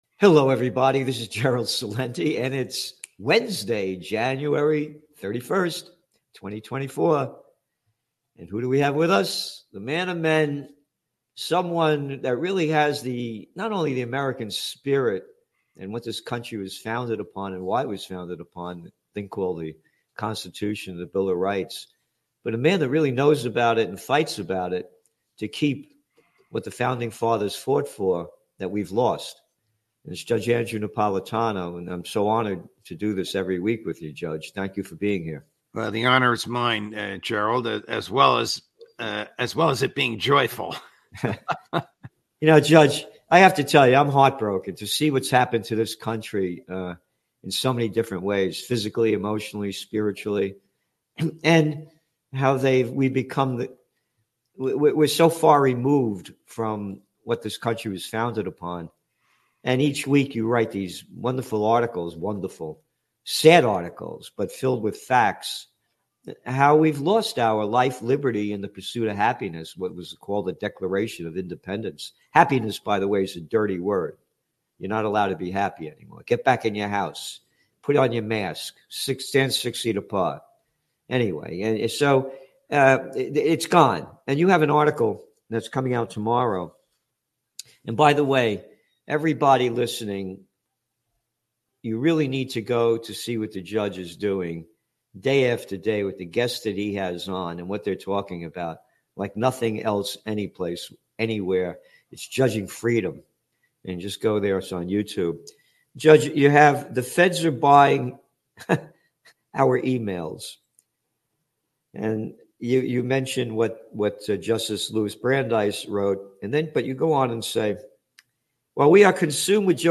SPREAD THE WORD MP3 Audio Summary ➡ Trends Journal with Gerald Celente and Judge Andrew Napolitano are worried about how the government is spying on people.